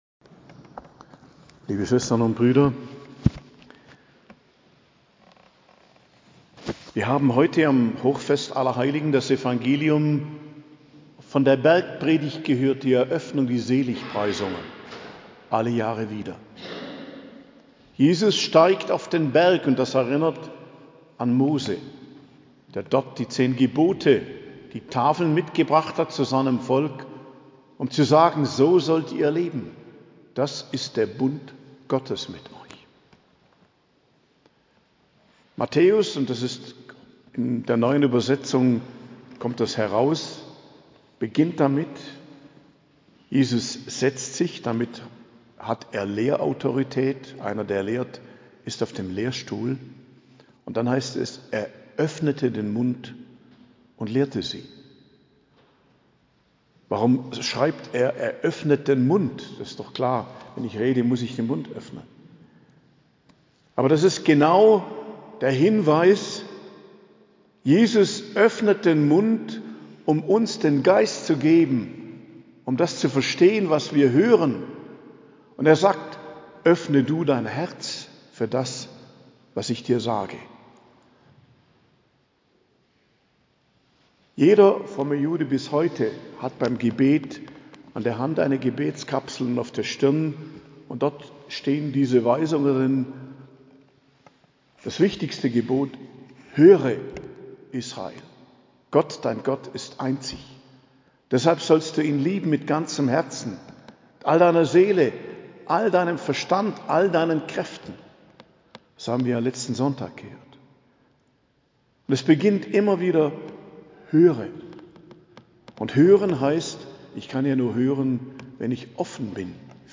Predigt zum Hochfest Allerheiligen, 1.11.2023